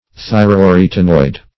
Search Result for " thyroarytenoid" : The Collaborative International Dictionary of English v.0.48: Thyroarytenoid \Thy`ro*a*ryt"e*noid\, a. (Anat.) Of or pertaining to both the thyroid and arytenoid cartilages of the larynx.
thyroarytenoid.mp3